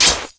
assets/pc/nzp/sounds/weapons/ballknife/shoot.wav at 29b8c66784c22f3ae8770e1e7e6b83291cf27485